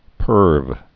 (pûrv)